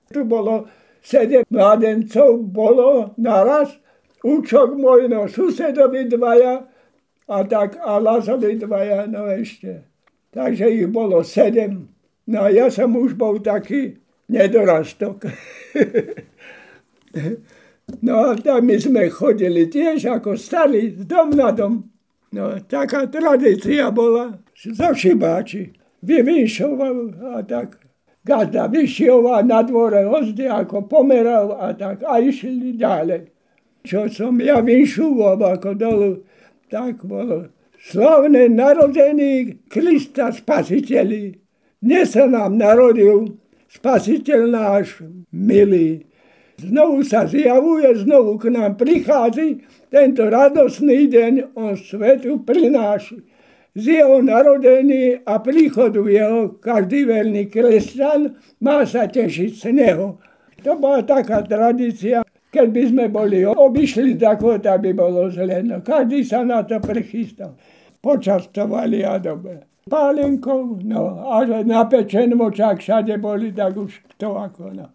Miesto záznamu Slatinské Lazy